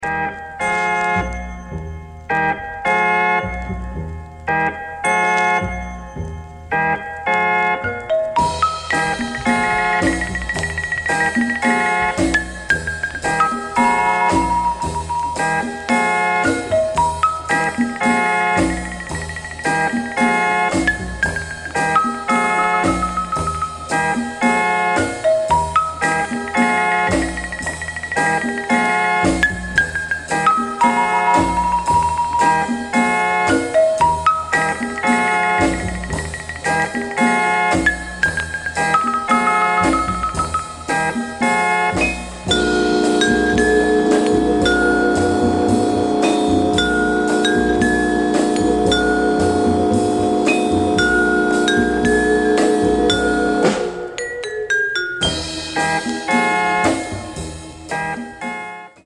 One of those albums samplers love, full of breaks and loops.